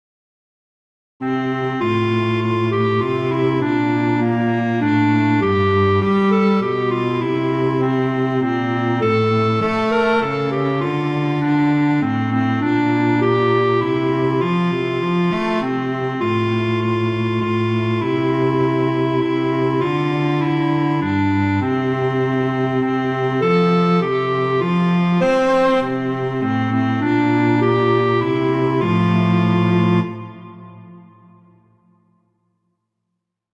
arrangements for clarinet and cello